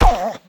Minecraft Version Minecraft Version latest Latest Release | Latest Snapshot latest / assets / minecraft / sounds / mob / wolf / sad / hurt1.ogg Compare With Compare With Latest Release | Latest Snapshot
hurt1.ogg